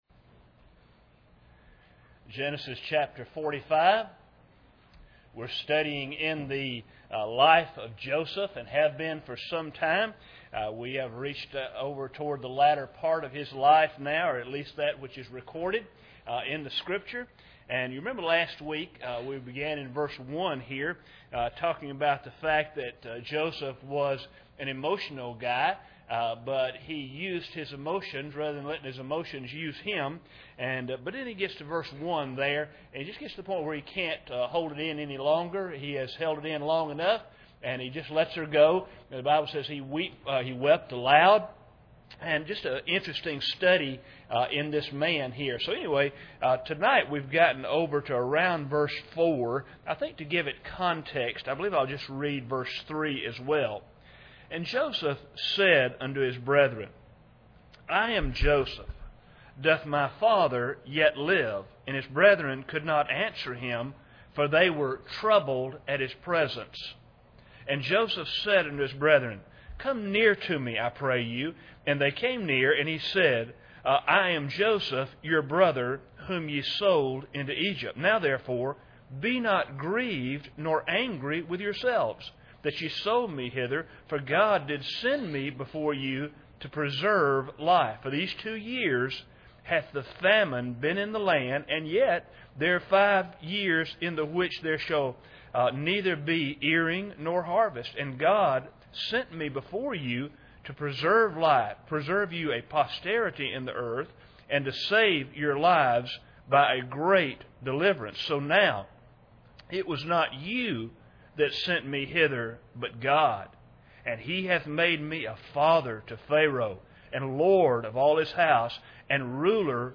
Genesis 45:3-8 Service Type: Sunday Evening Bible Text